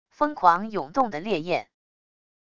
疯狂涌动的烈焰wav音频